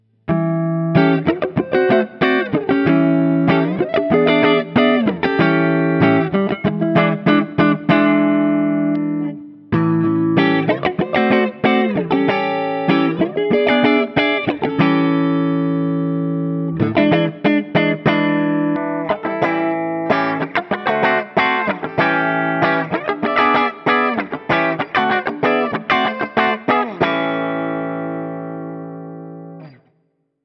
ODS100 Clean
7th Chords
As usual, for these recordings I used my normal Axe-FX II XL+ setup through the QSC K12 speaker recorded direct into my Macbook Pro using Audacity. I recorded using the ODS100 Clean patch, as well as the JCM-800 and one through a setting called Citrus which is a high-gain Orange amp simulation.
For each recording I cycle through the neck pickup, both pickups, and finally the bridge pickup. All knobs on the guitar are on 10 at all times.